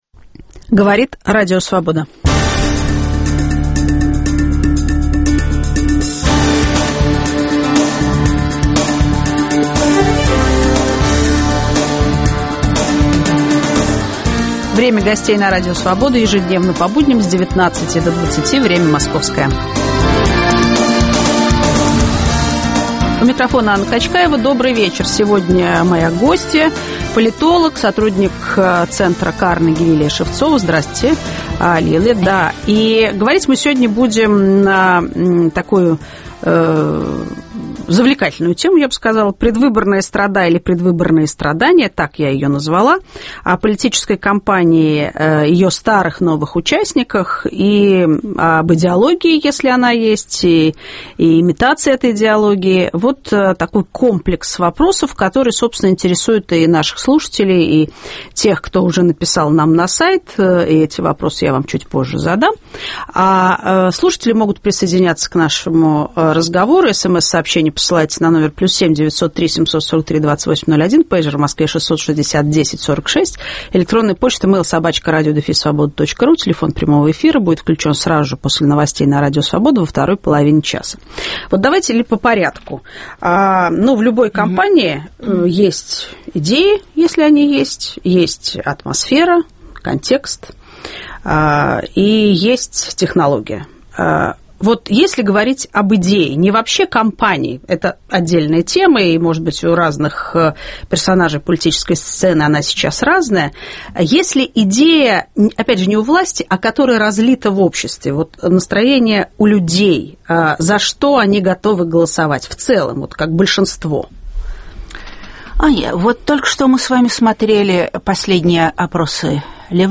Сотрудник Московского центра Карнеги политолог Лилия Шевцова